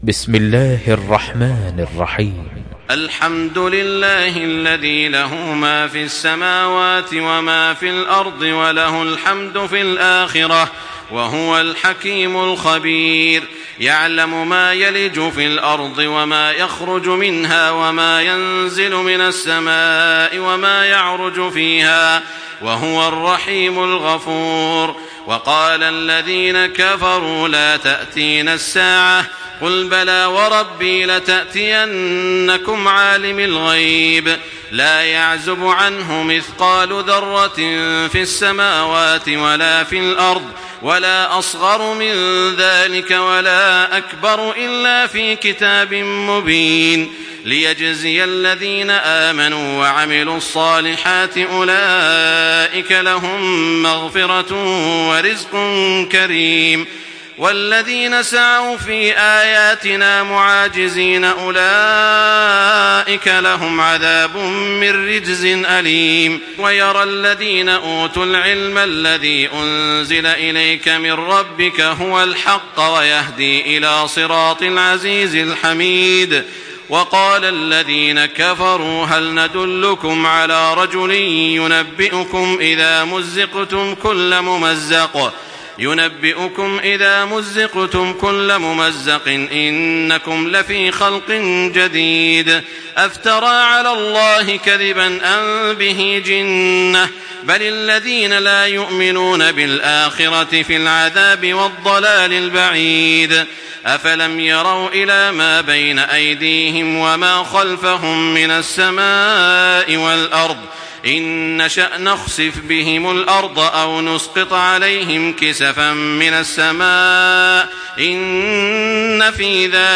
Surah سبأ MP3 by تراويح الحرم المكي 1425 in حفص عن عاصم narration.